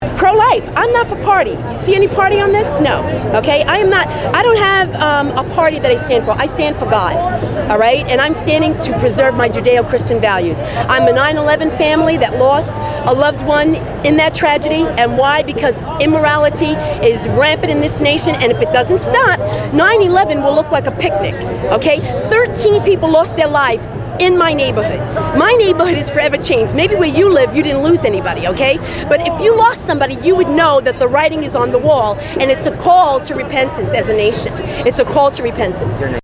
Interview with a counter-demonstrator at today's Planned Parenthood march and rally.
§Interview part 4.